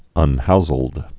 (ŭn-houzəld)